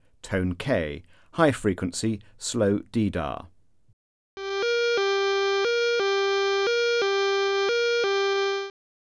Alert Tone: K